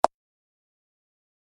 KeypressStandard.ogg